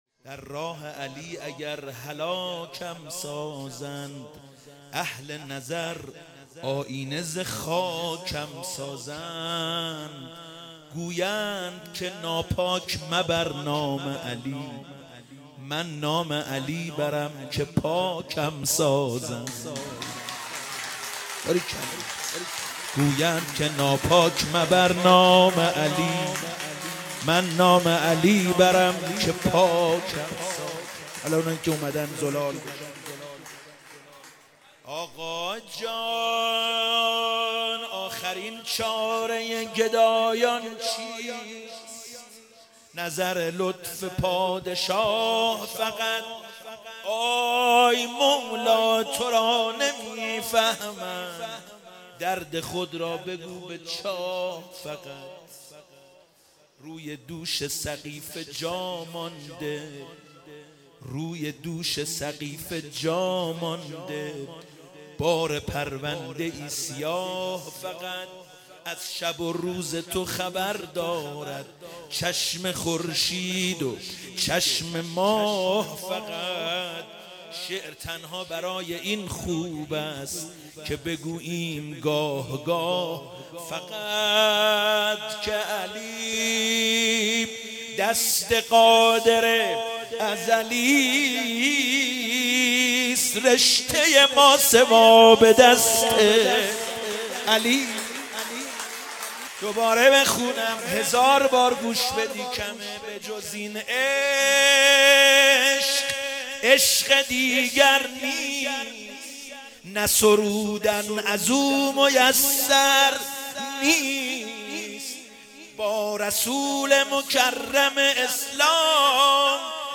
عید سعید غدیر